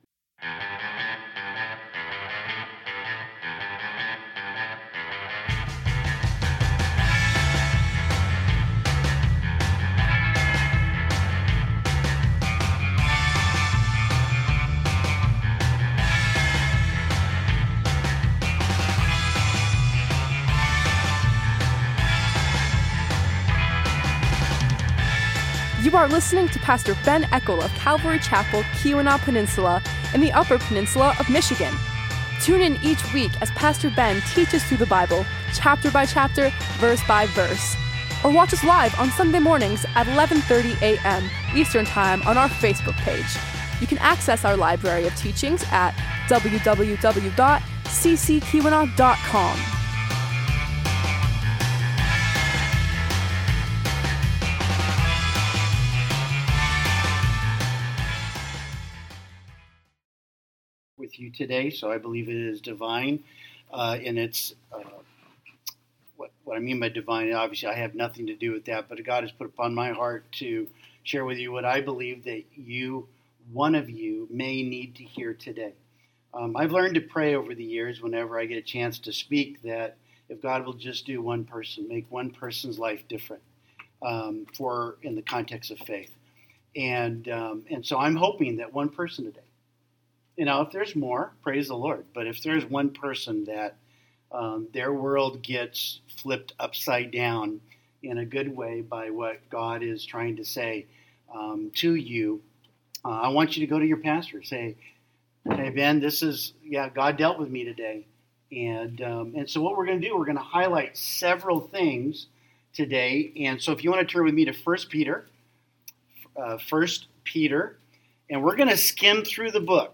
Series: Guest Speaker Service Type: Sunday Morning « “Death of a Nation” 2 Kings 24-25 “Almighty God” Genesis 1 1-2 »